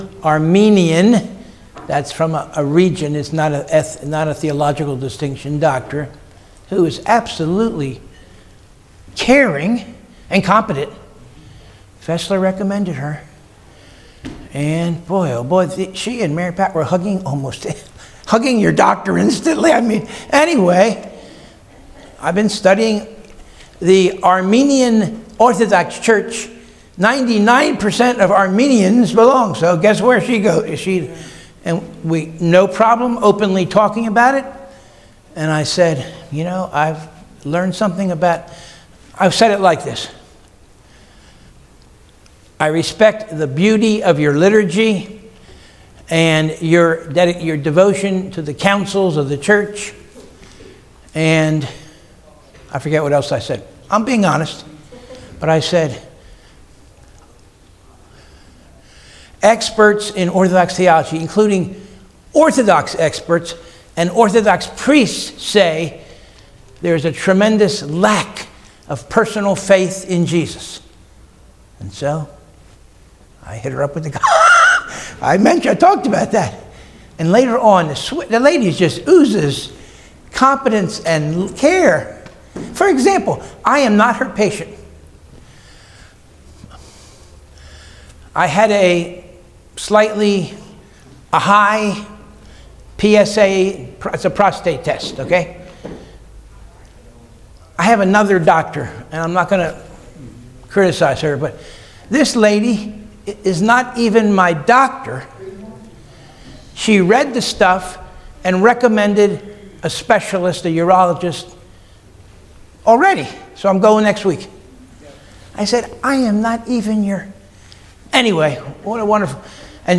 Sunday School 3-1-2026 - Covenant of Grace Church